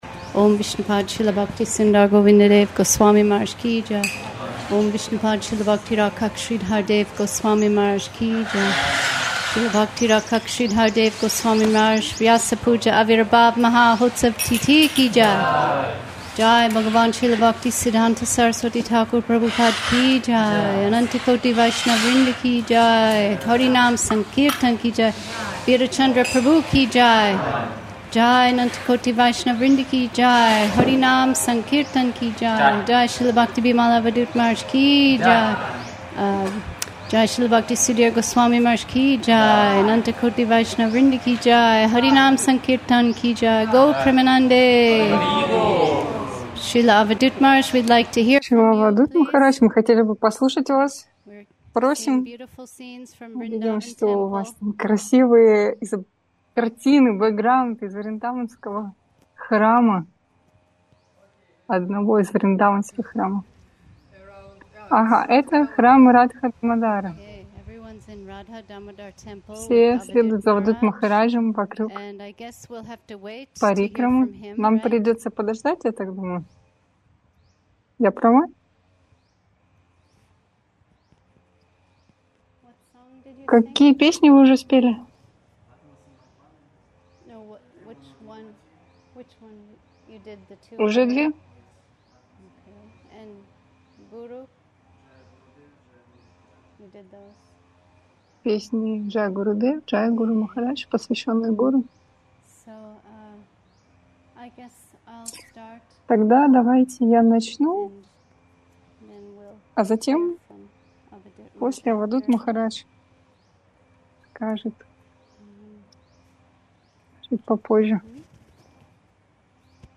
Явление Шрилы Шридхара Махараджа. Праздничный ZOOM. 26 октября 2024 года.